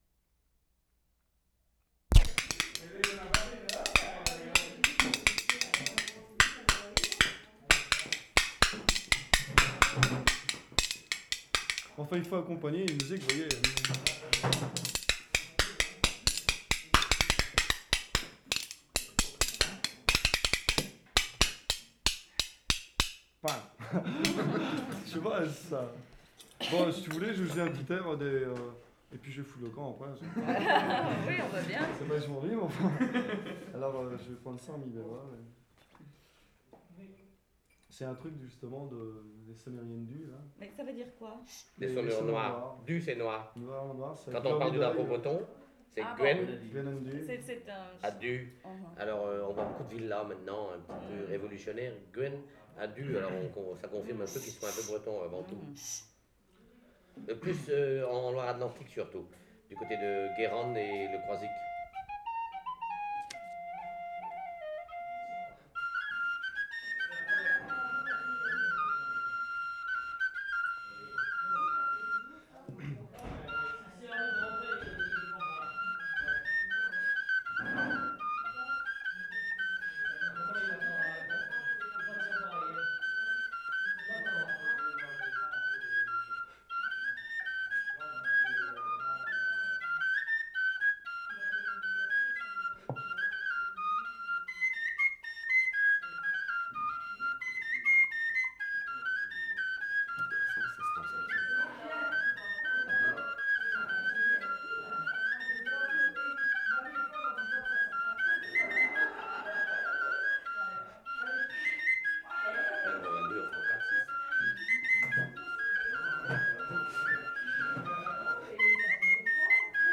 WORLD SOUNDSCAPE PROJECT TAPE LIBRARY
Lesconil, France April 12/75
MORE LIVE MUSIC, spoon and one flute piece.
Metal flute with plastic mouthpiece; sounds like a whistle or song flute. Demonstration of spoon playing, then another flute piece.